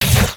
bullet_hit_mutant.wav